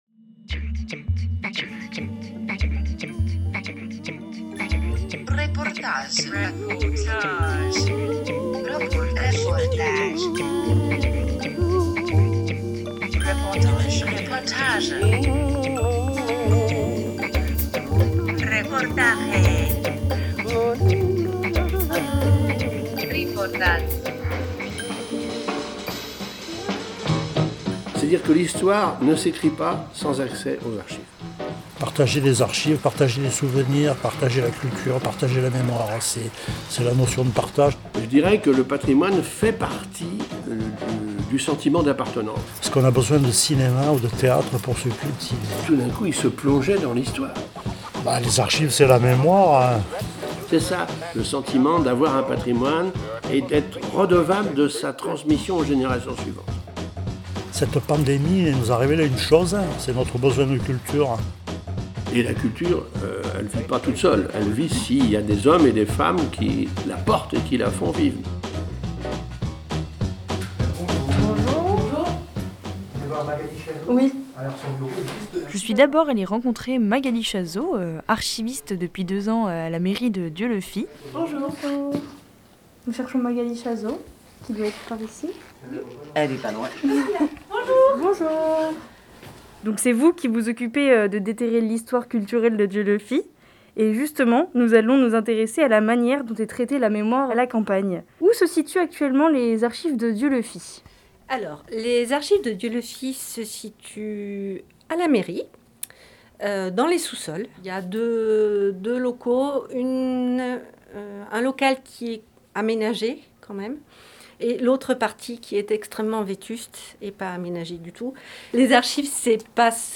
2 mars 2021 8:00 | Interview, reportage
Entre le crépitement des pages séculaires et le sifflement des canalisations, des rayons remplis de cartons trônent silencieusement.